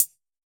UHH_ElectroHatD_Hit-12.wav